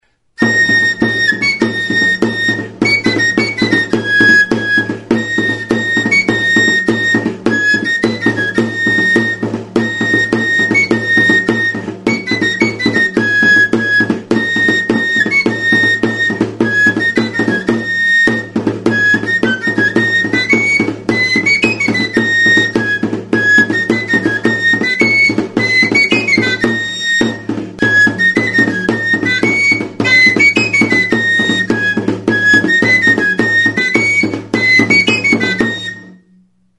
(Grabazioa 311 zenbakia duen txistu metalikoaren laguntzarekin egin da)
DANBOLINA; TTUNTTUNA
Membranófonos -> Golpeados -> Tambores con palos Situación